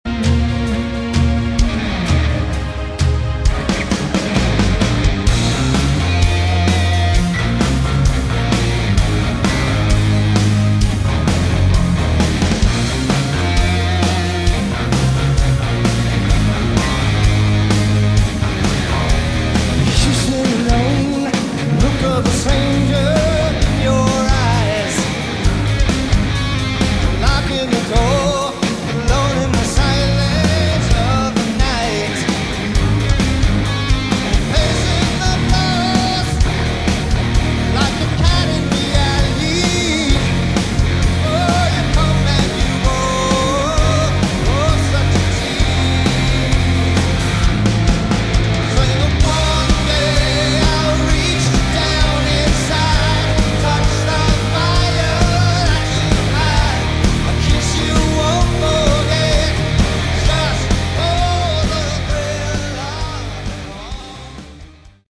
Category: AOR
guitar, vocals
drums
vocals
bass, vocals
keyboards
So awesome to see this legendary aor band on stage.